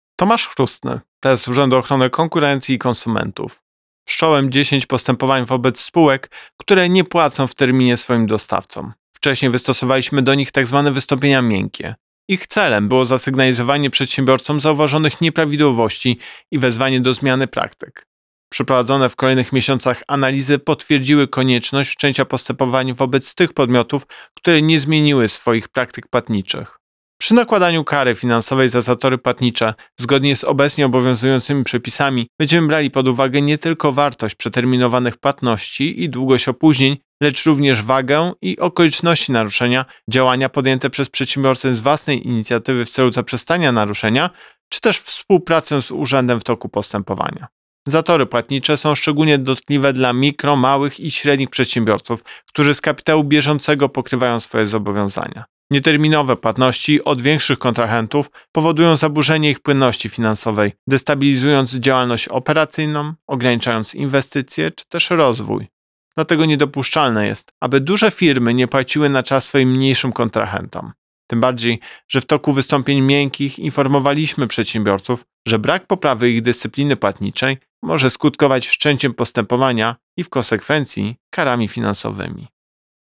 Wypowiedź Prezesa UOKiK Tomasza Chróstnego Jakie kary grożą przedsiębiorcom?